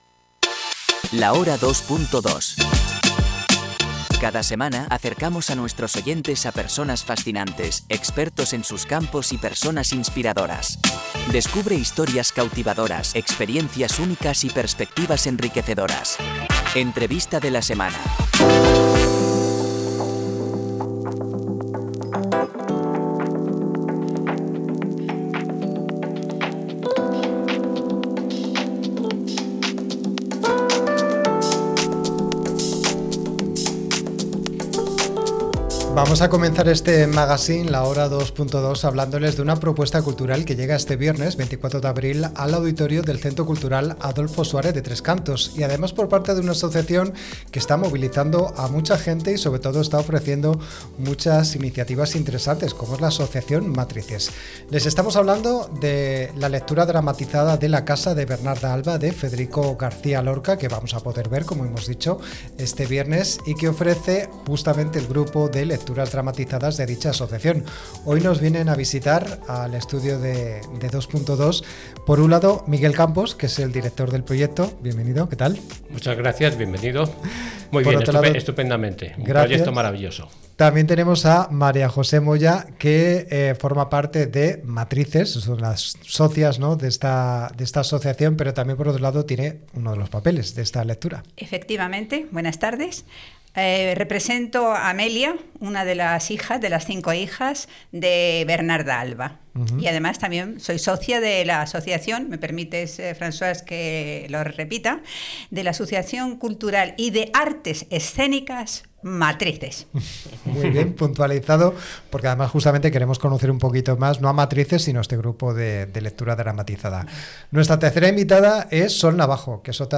La Hora Dos Punto Dos - Entrevista grupo Matrizes - 2.2 Radio